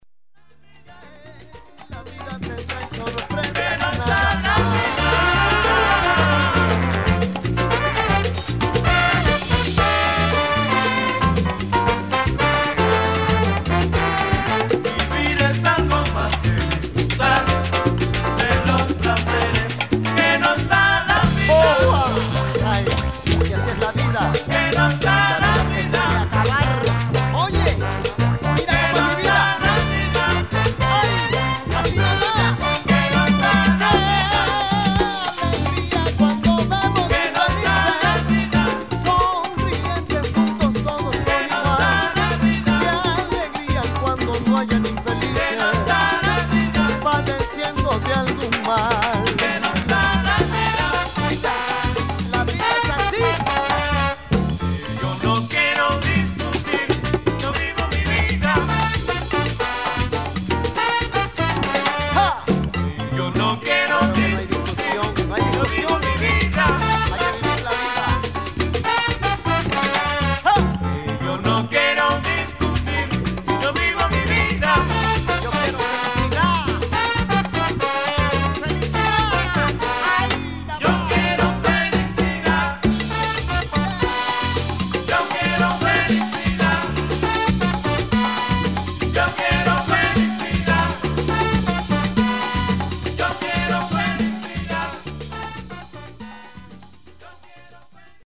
80S Cuban Son